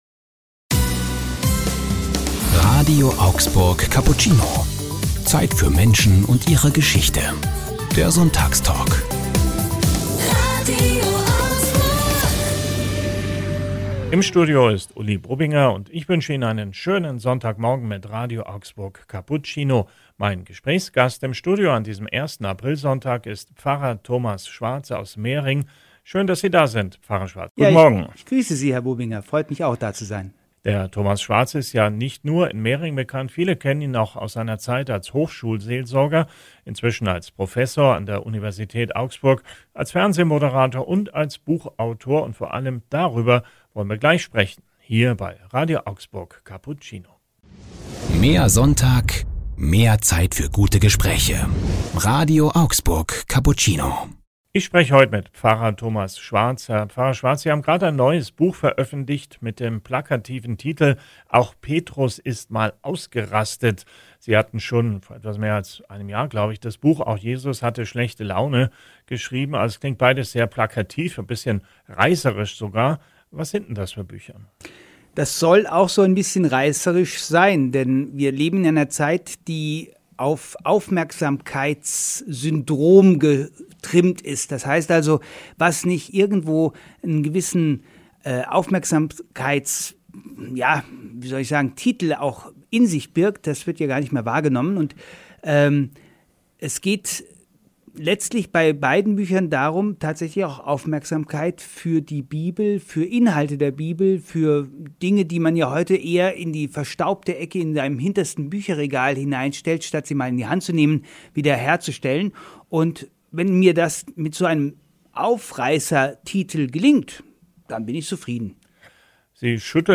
dem Sonntagstalk bei RADIO AUGSBURG.